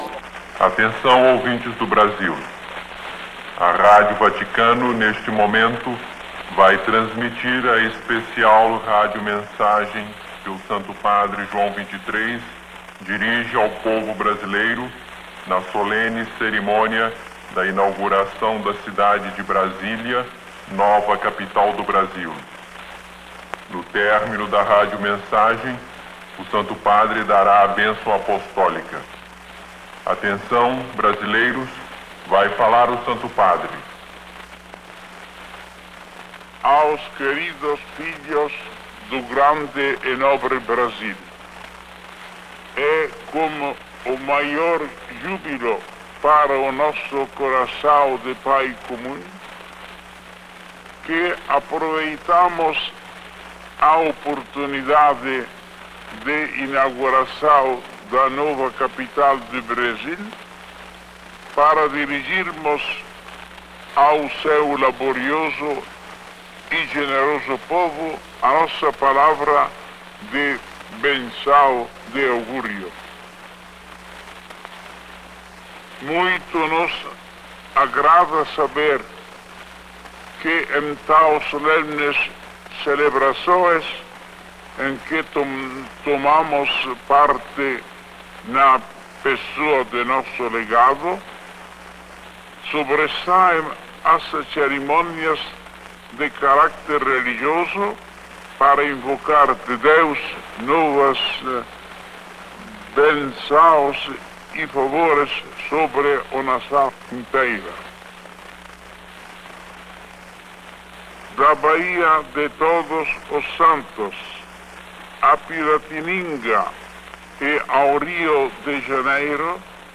Resumo Descrición Mensagem do Papa João XXIII ao povo brasileiro.wav Português: Mensagem do Papa João XXIII ao povo brasileiro.
Mensagem_do_Papa_João_XXIII_ao_povo_brasileiro.wav